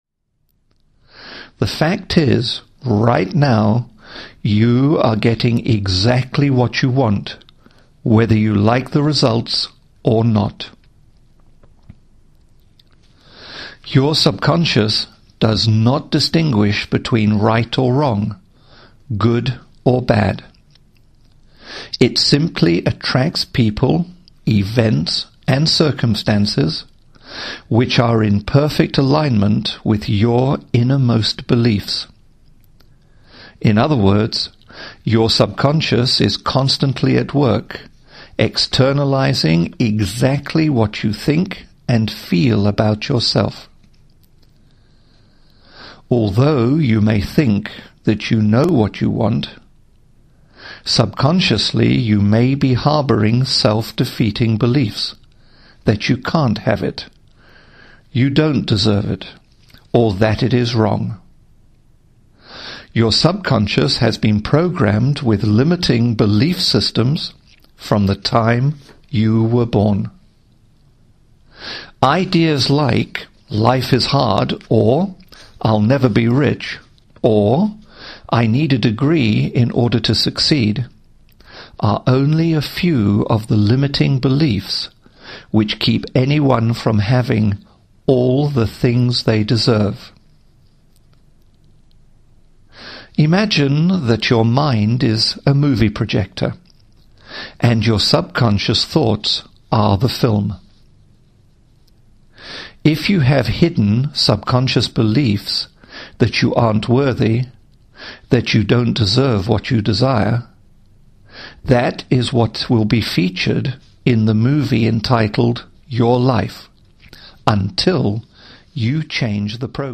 Audio Induction; Audio Affirmations;
No Music Ambience; No Nature Ambience
Lay back and be guided into a pleasant state of deep relaxation through voice only.
The Get What You Want program contains only voice, and you will be guided through the session.